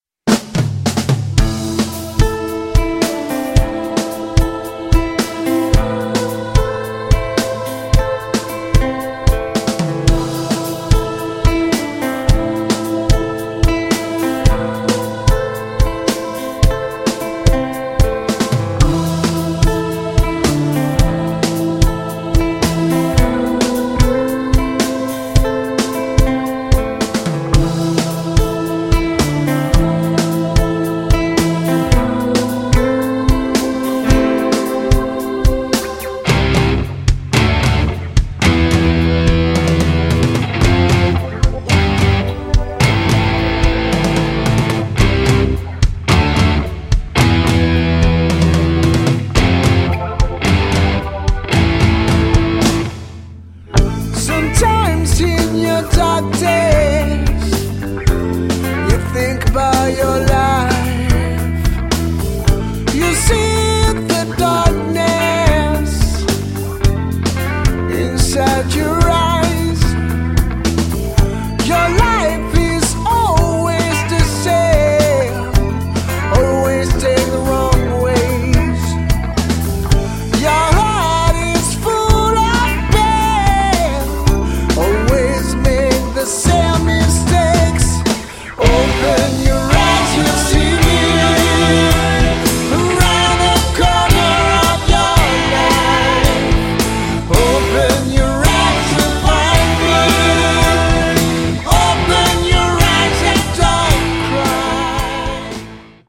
Enregistrement Studio Taurus (GE)